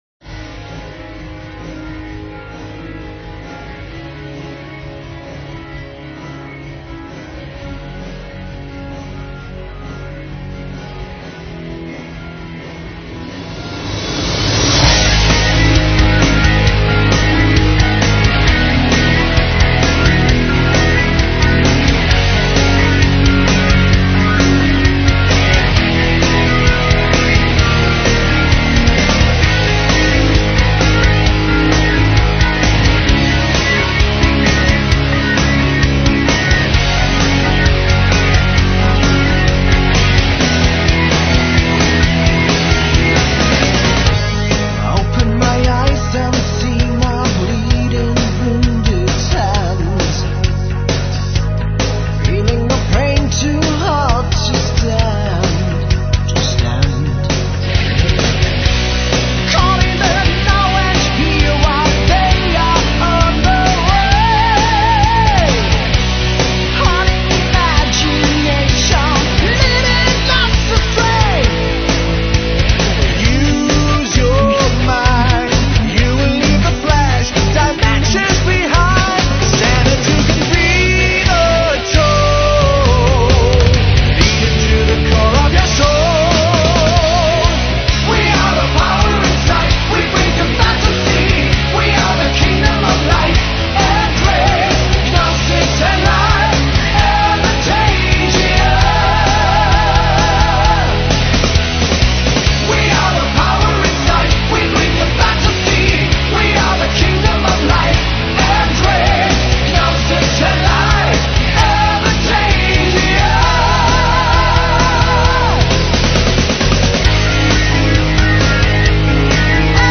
Павер металл